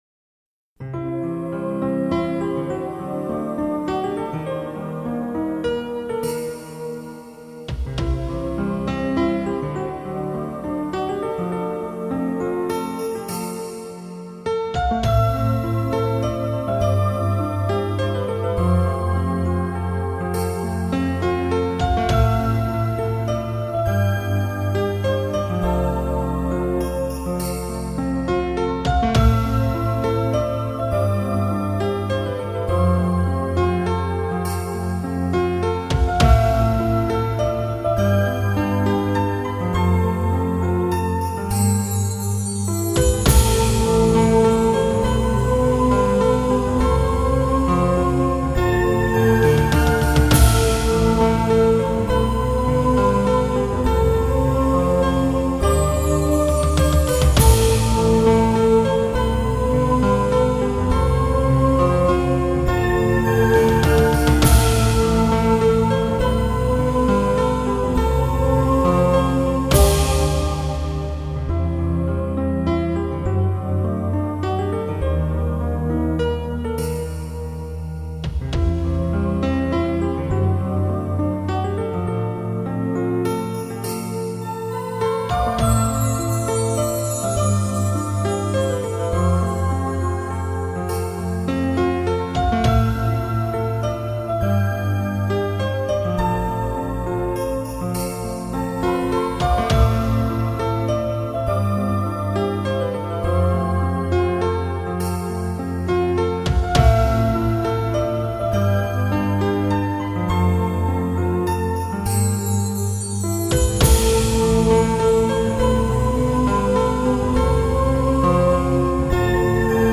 有时听点轻松的音乐、脑袋可以得到真正的体息，一天承受的种种压力可以得到一定程度的舒缓。